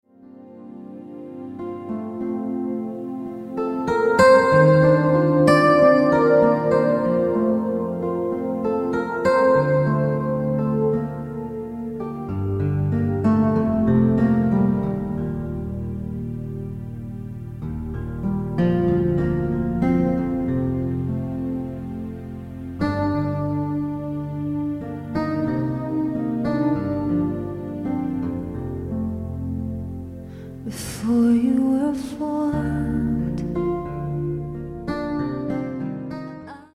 spontaneous worship with vocals and flowing keyboard.